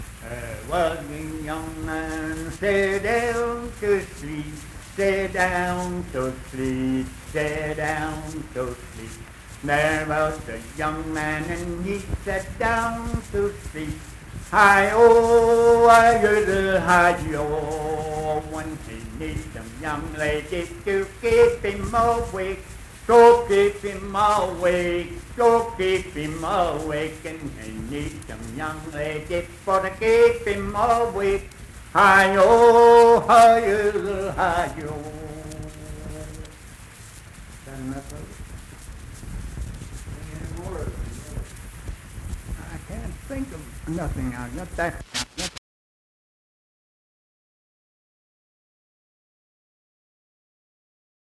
Unaccompanied vocal music performance
Verse-refrain 2(4w/R).
Voice (sung)